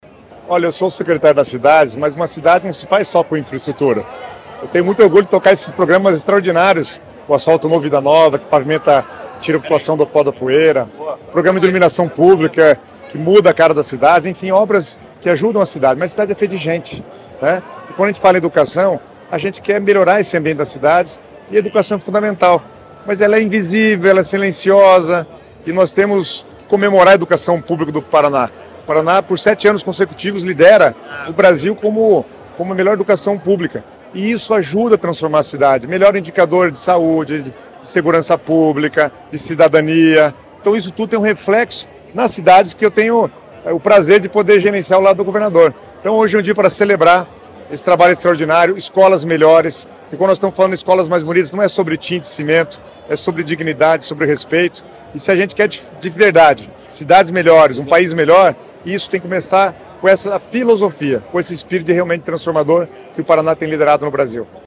Sonora do secretário das Cidades, Guto Silva, sobre o pacote de investimentos e uniformes para toda a rede estadual de educação